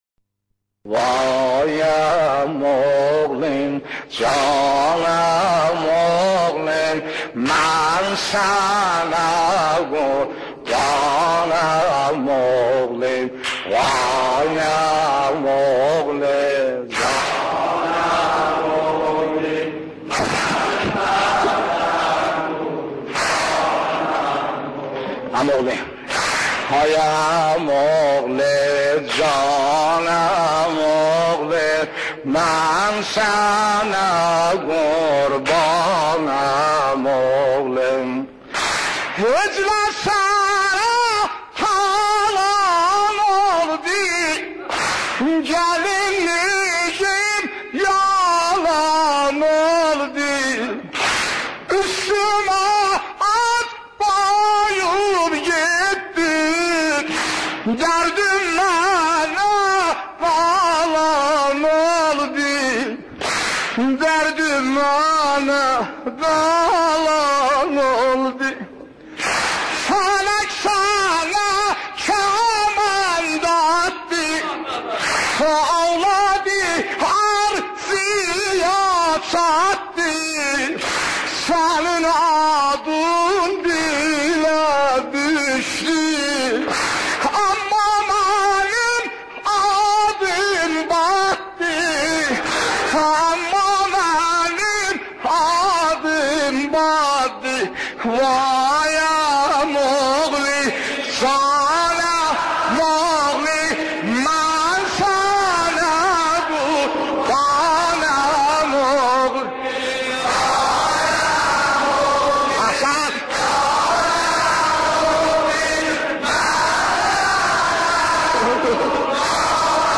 مداحی آذری نوحه ترکی